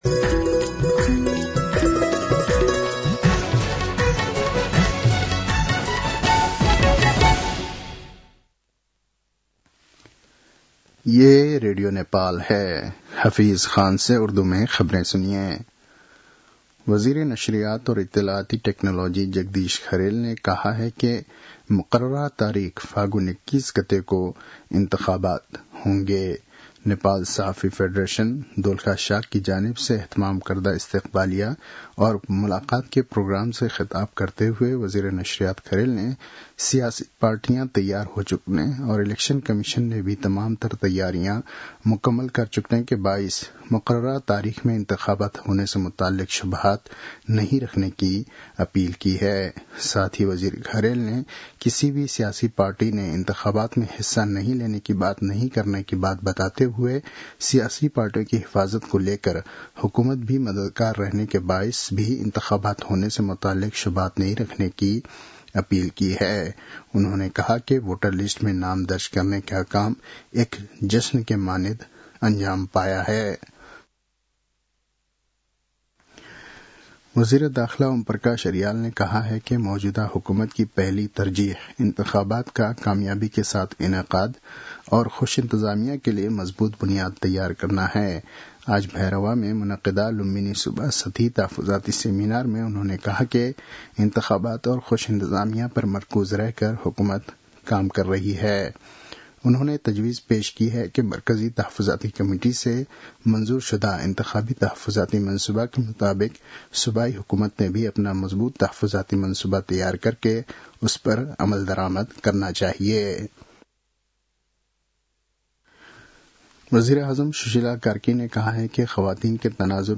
उर्दु भाषामा समाचार : ९ मंसिर , २०८२